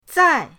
zai4.mp3